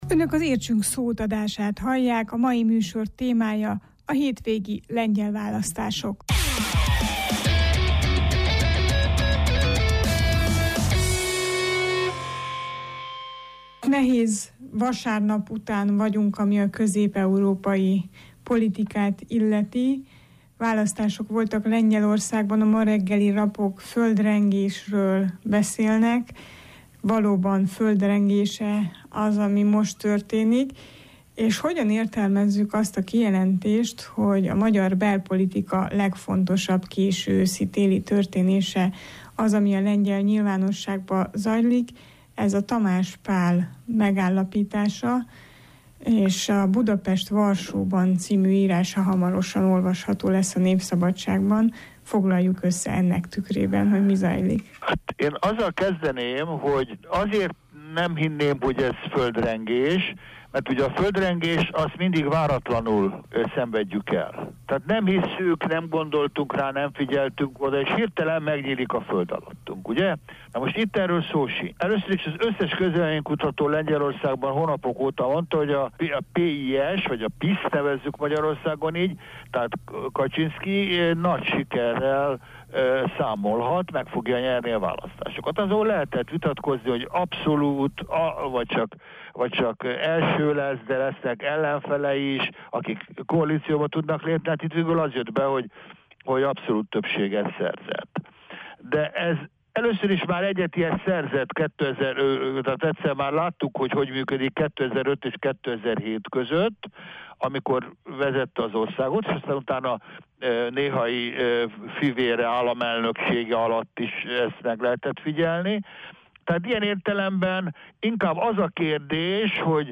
szociológus a lengyel választásokat elemzi.